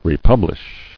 [re·pub·lish]